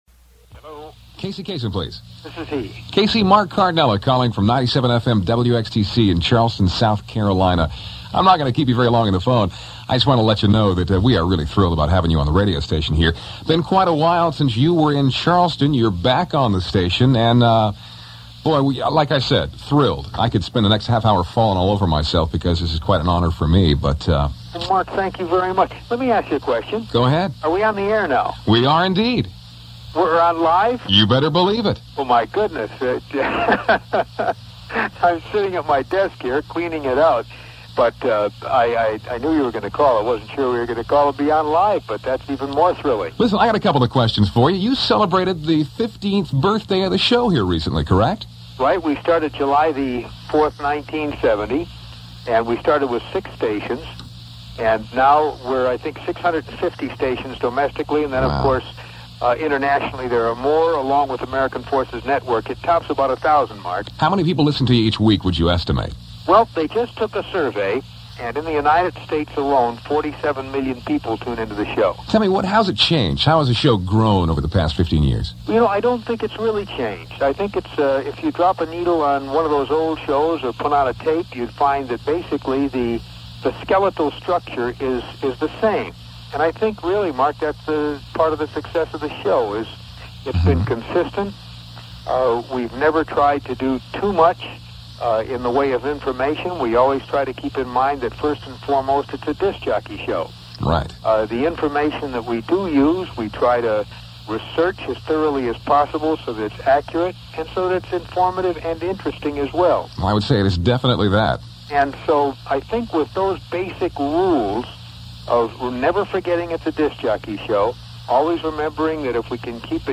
CASEY KASEM INTERVIEW
wxtc-kasem-interview.mp3